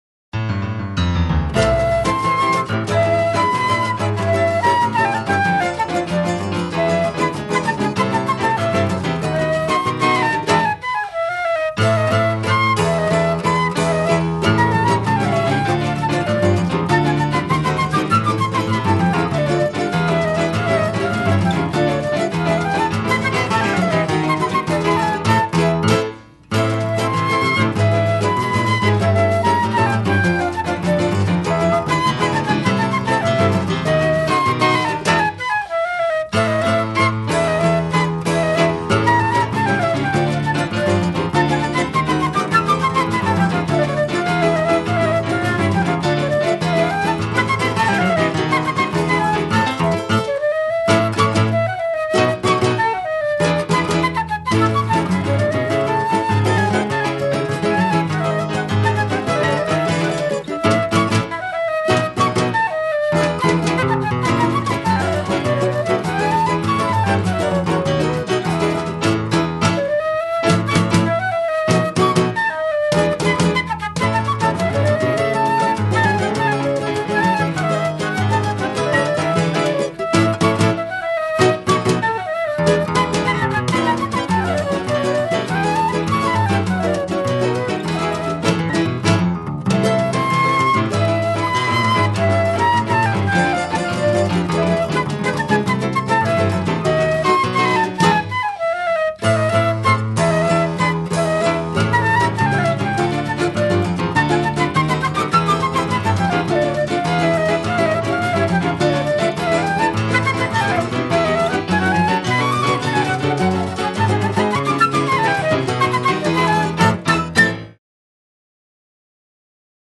CD gravação analógica em 2001
flauta
piano
violão 7 cordas
cavaquinho
percussão